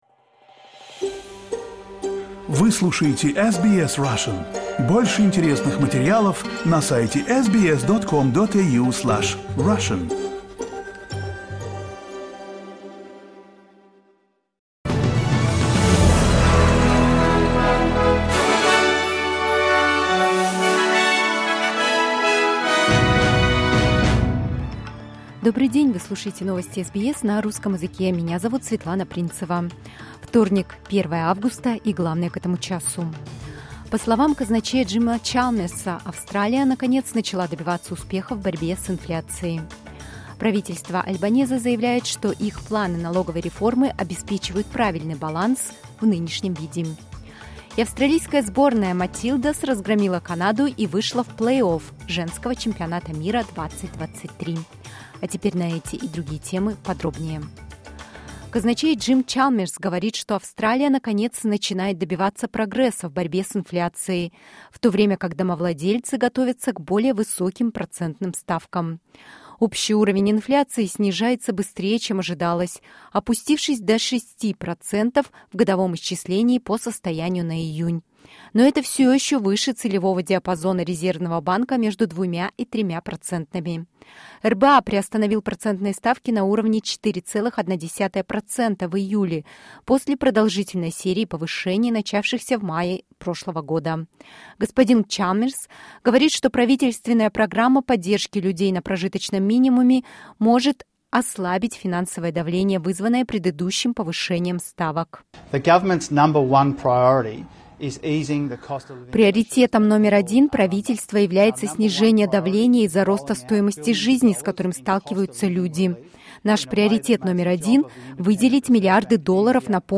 SBS news in Russian — 01.08.2023